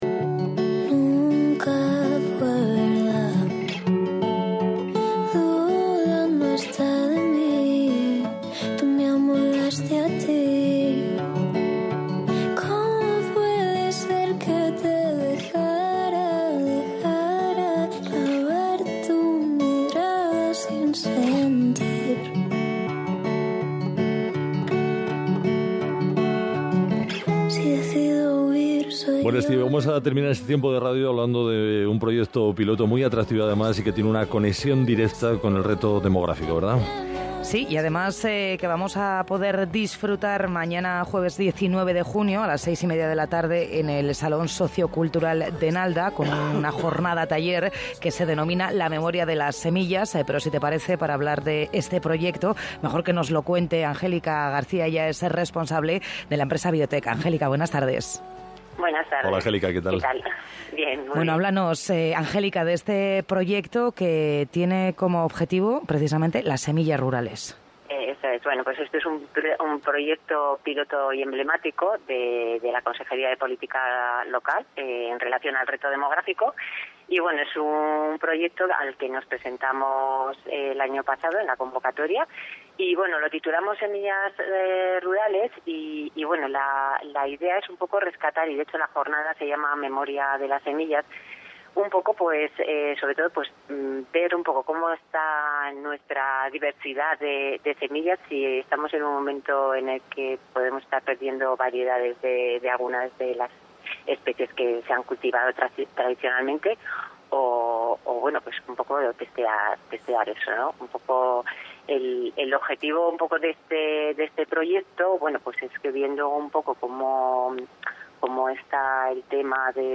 Entrevista de radio Hoy por Hoy, Cadena ser 18/06/2025 (ESCUCHAR)